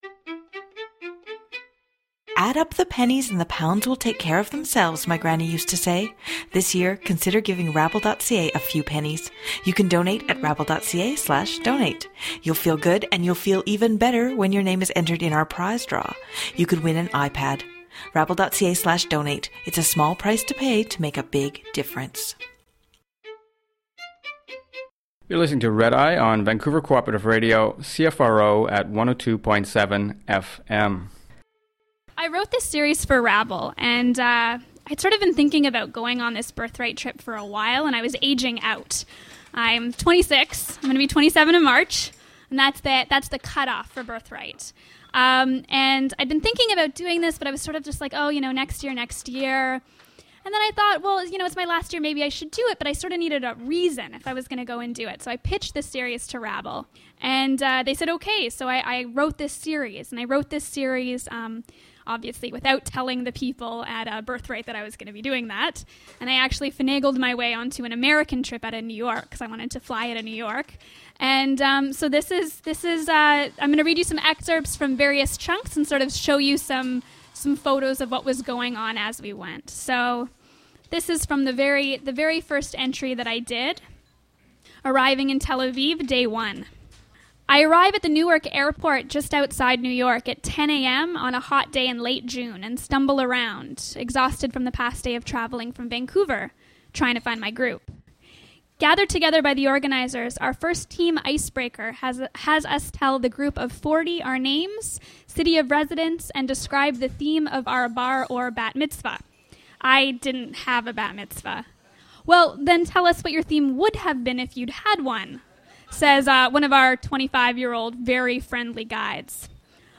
Genre: Speech